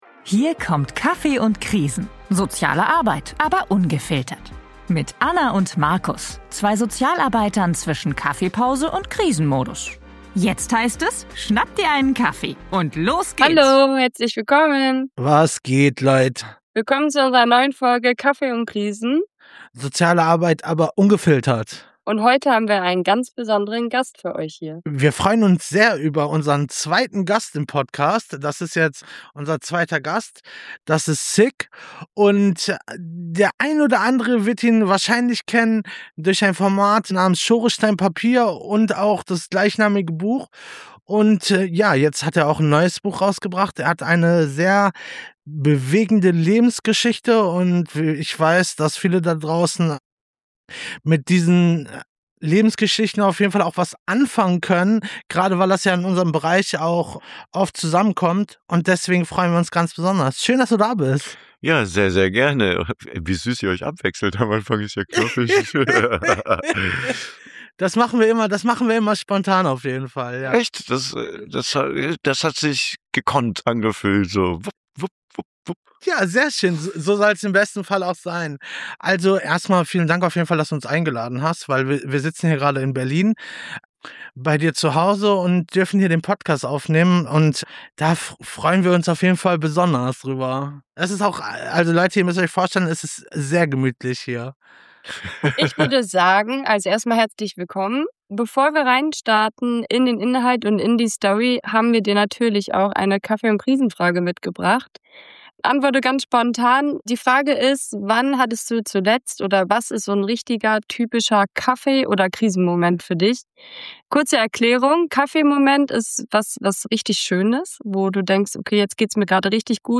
TEIL 1! In dieser Folge haben wir einen ganz besonderen Gast: